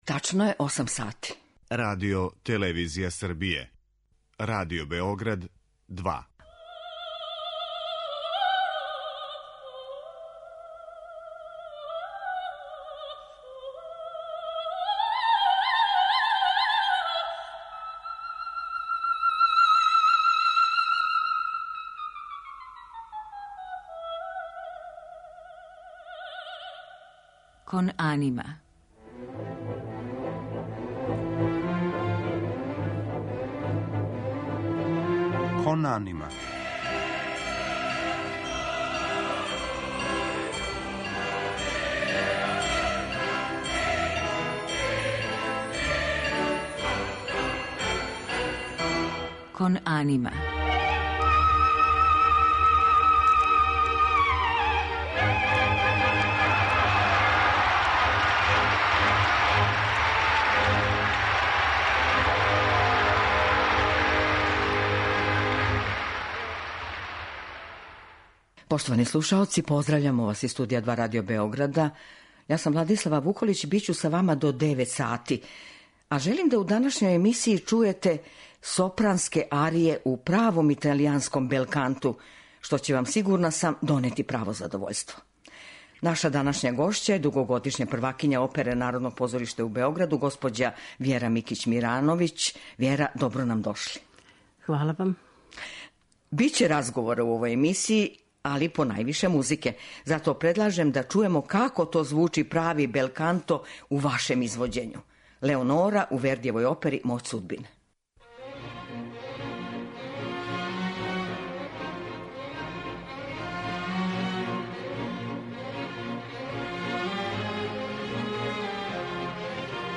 У данашњој емисији слушаћете сопранске арије у правом италијанском 'белканту'.